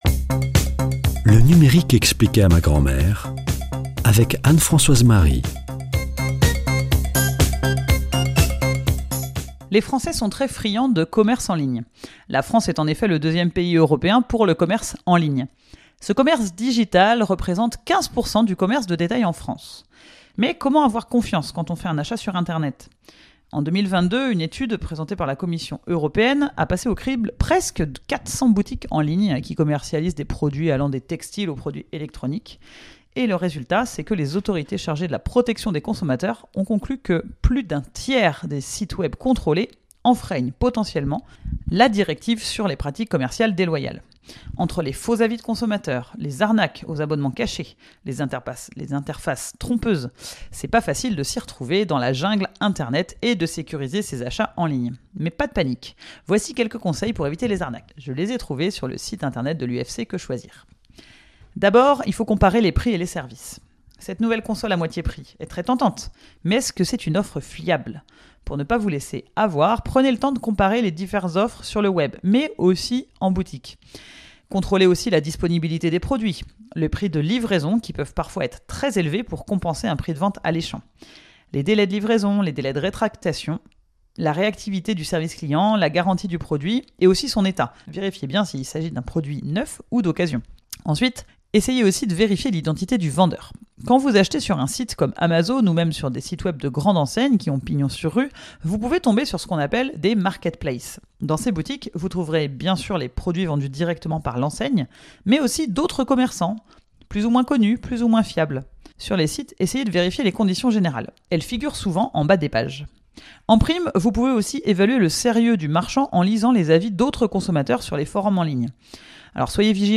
[ Rediffusion ]
Présentatrice